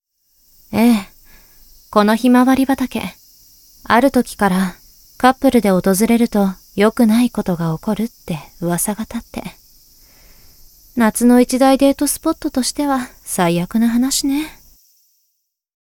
ハコネクト所属メンバーが収録した「夏のおでかけ」をテーマにしたコンセプトボイスを是非お楽しみください！
ボイスサンプル